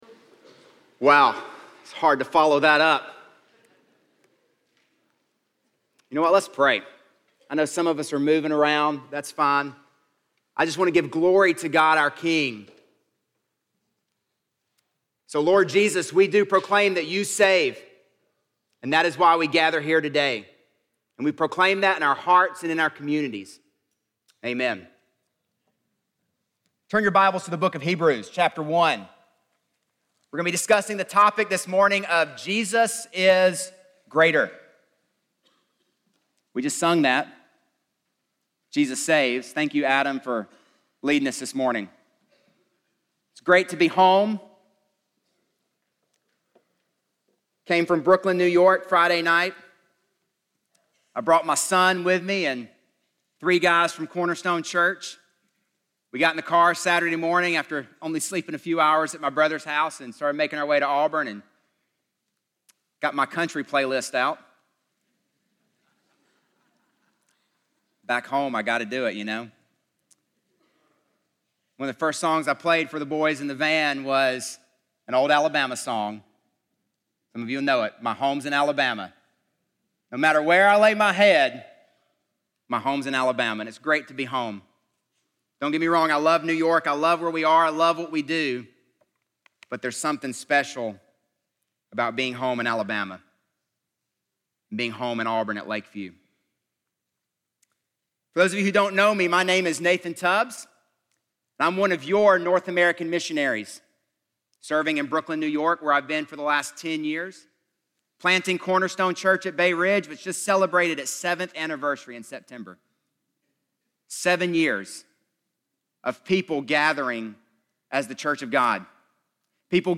Stand Alone Sermons Passage